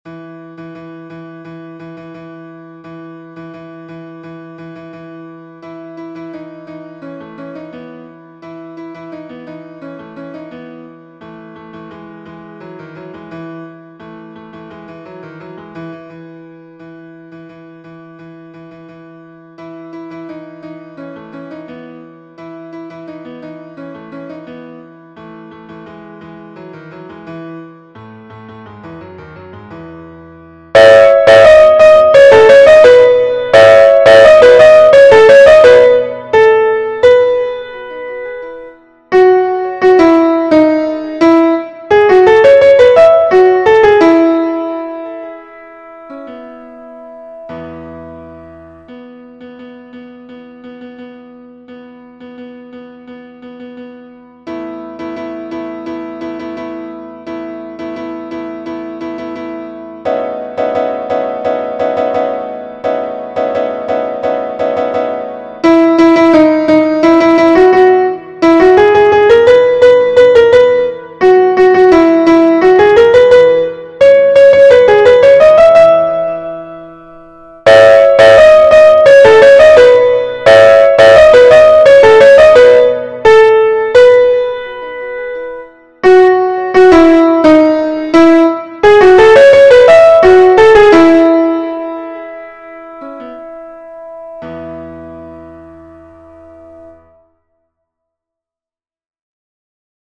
- Chant pour 4 voix mixtes SATB
MP3 versions piano
Soprano Version Piano